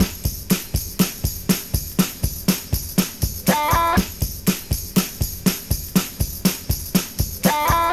• 121 Bpm Drum Loop B Key.wav
Free breakbeat sample - kick tuned to the B note.
121-bpm-drum-loop-b-key-a47.wav